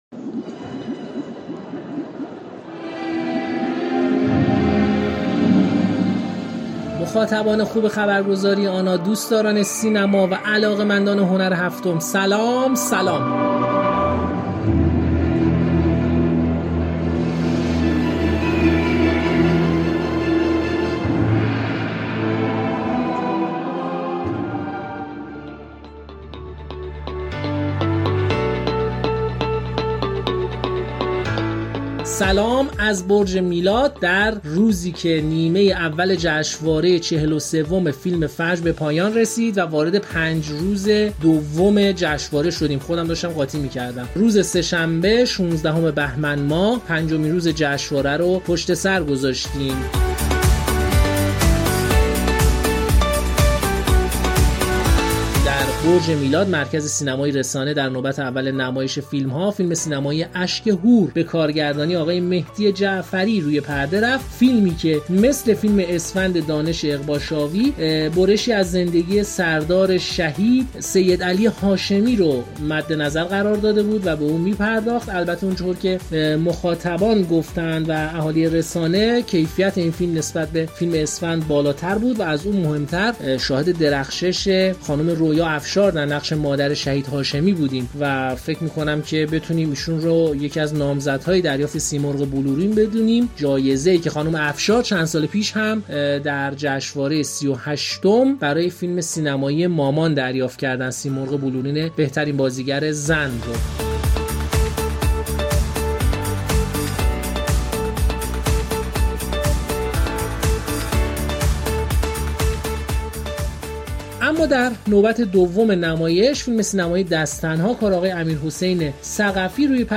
گزارش و اجرا